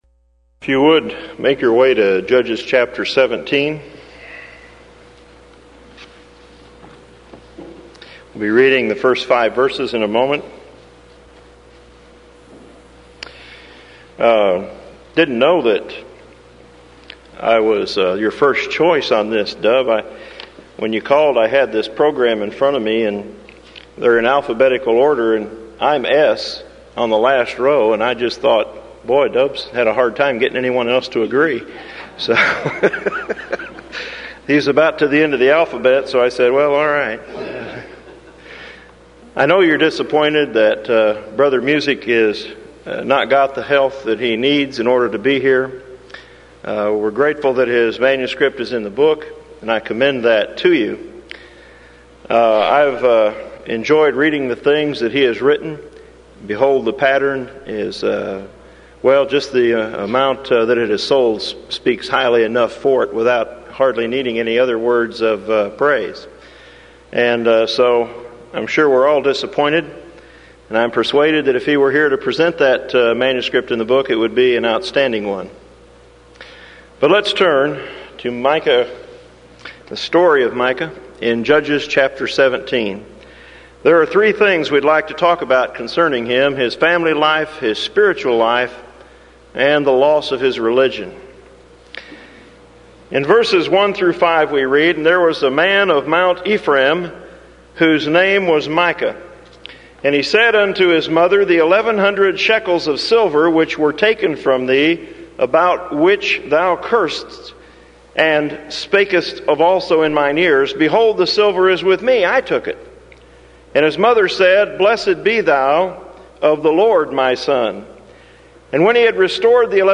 Event: 1994 Denton Lectures Theme/Title: Studies In Joshua, Judges And Ruth
lecture